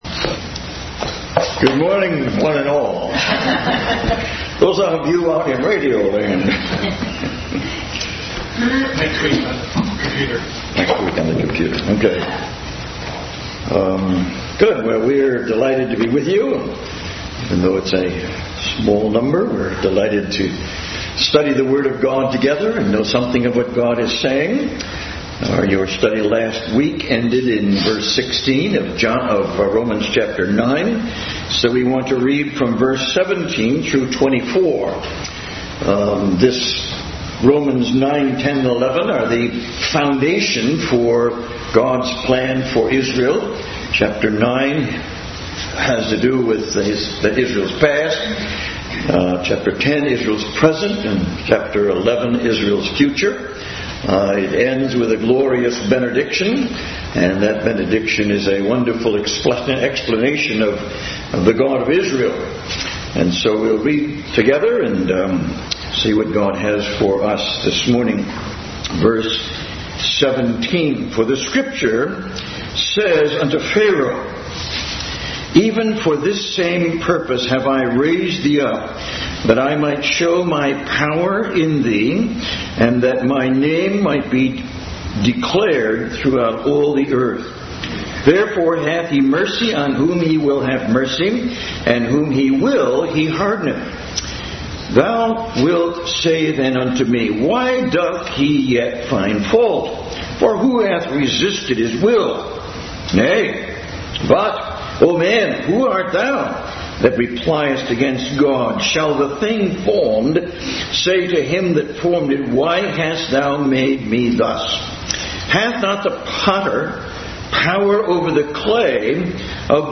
Romans 9:17-24 Service Type: Sunday School Bible Text